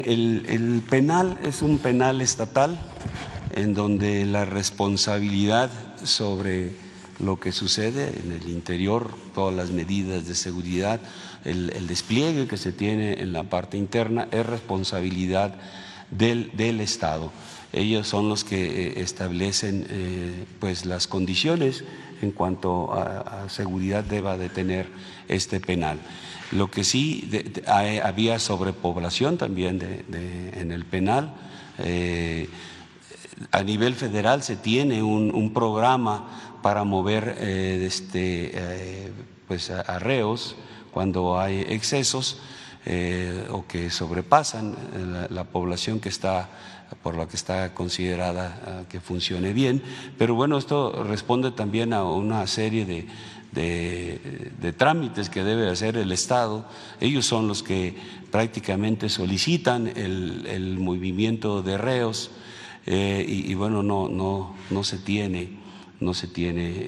Chihuahua, Chih.- Luis Crescencio Sandoval, General Secretario de la Secretaría de la Defensa Nacional (Sedena), desde Conferencia de Prensa Matutina, en Palacio Nacional, aseguró que el Gobierno del Estado de Chihuahua, no solicitó apoyo de las corporaciones de seguridad federal para el traslado de